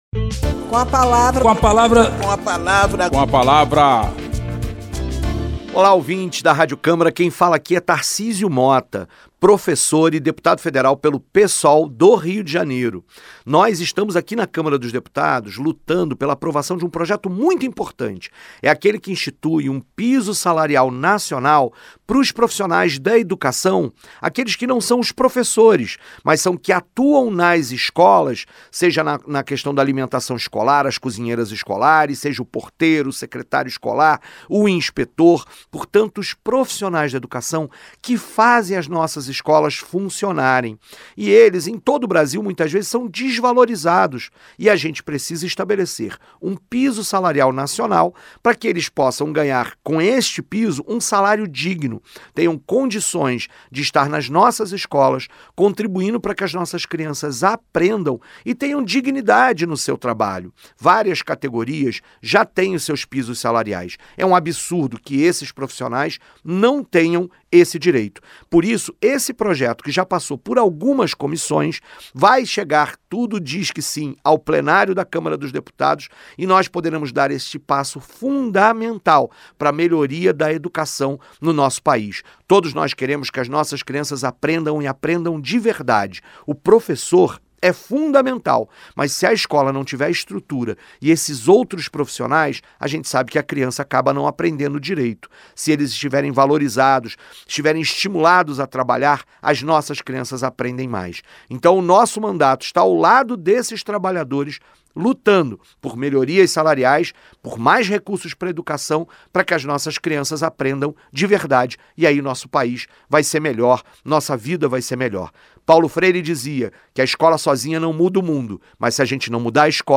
O deputado Tarcísio Motta (Psol-RJ) defende a aprovação o projeto que trata do piso salarial nacional para os profissionais que integram o sistema educacional brasileiro.
Espaço aberto para que cada parlamentar apresente aos ouvintes suas propostas legislativas